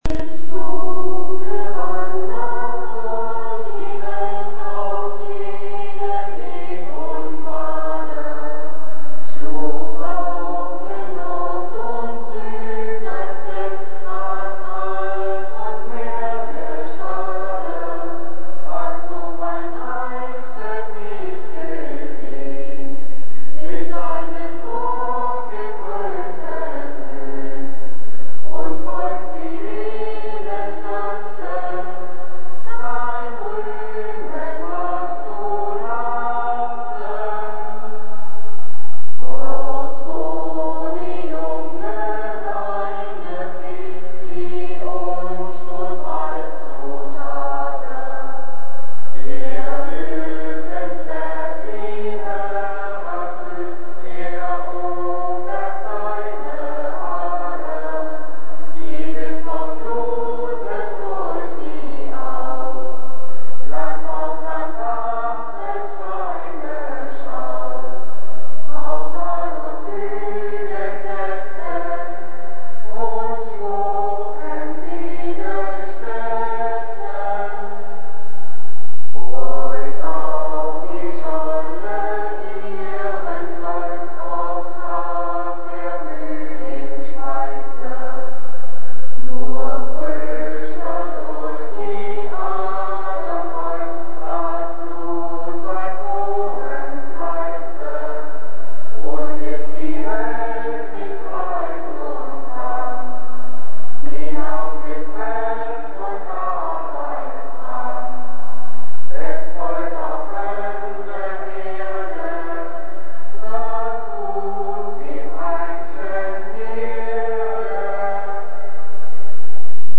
eichsfeldlied-chor.mp3